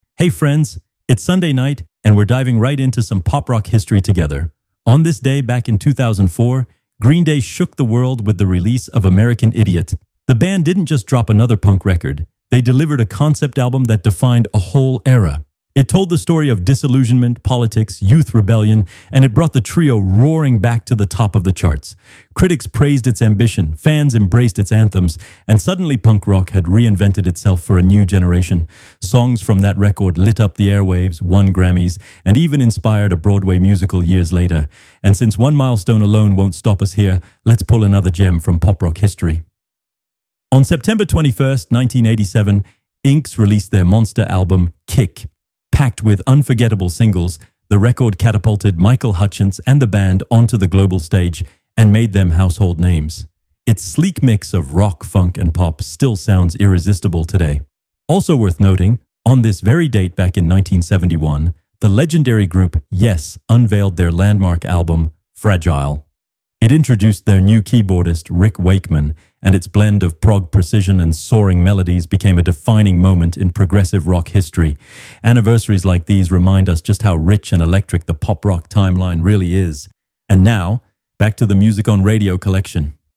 A lively column that mixes memories, anecdotes, and discoveries for a true daily journey through the history of Pop Rock fans' favorite artists, from the 70s to today.